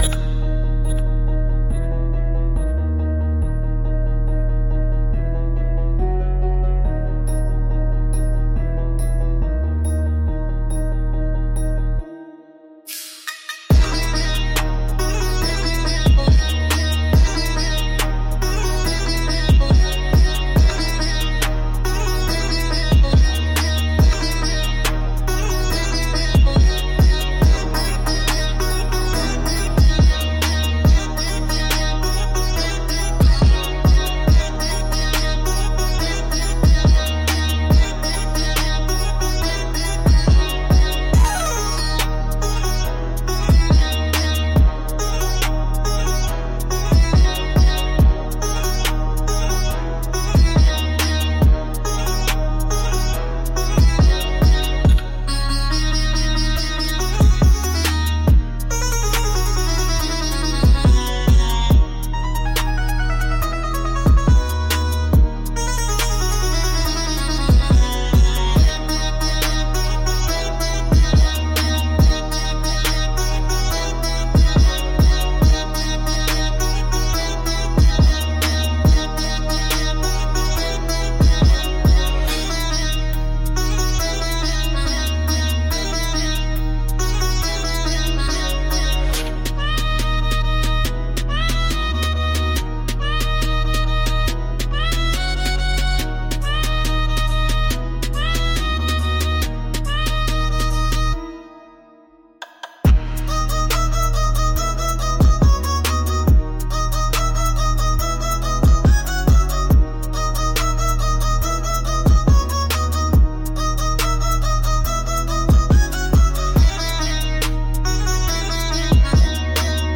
E♭ Minor – 140 BPM
Classical
Drill
Trap